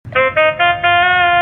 School Ringtone Sound Effect Download: Instant Soundboard Button
The School Ringtone is a popular audio clip perfect for your soundboard, content creation, and entertainment.